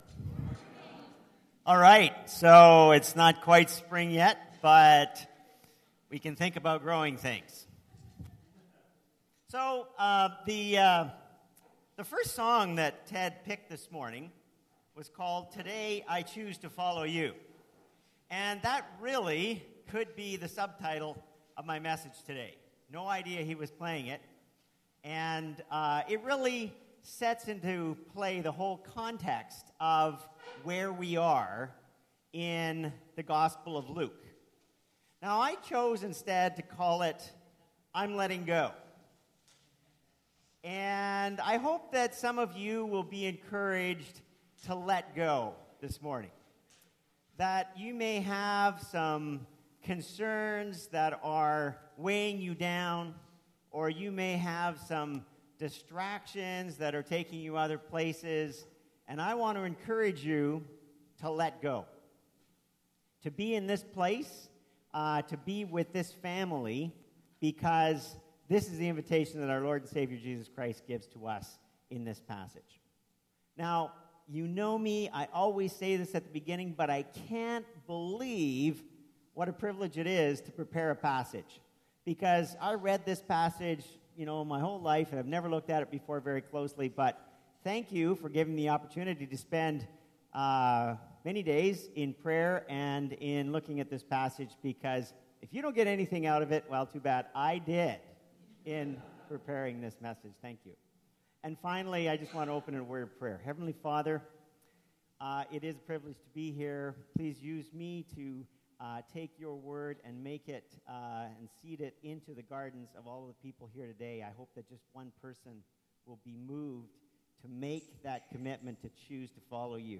This sermon is based on Luke 18:15-17 & 35-43.